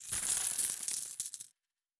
Coins.wav